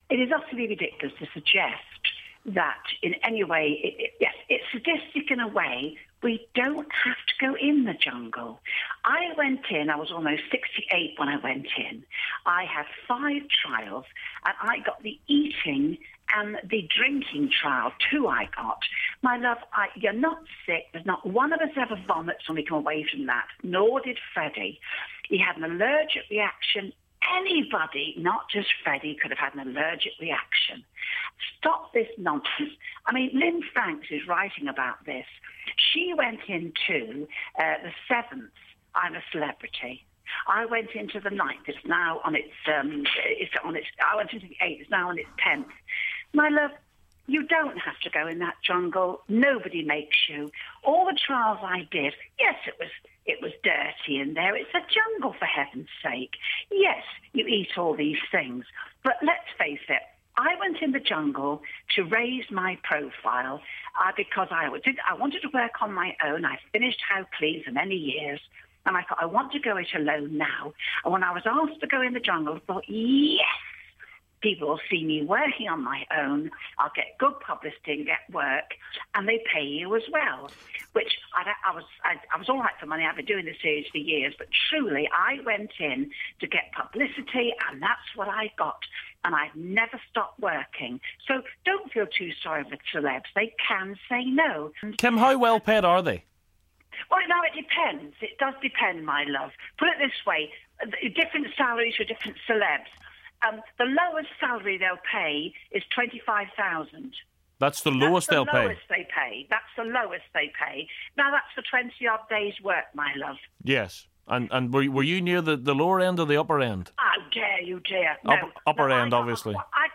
Former contestant Kim Woodburn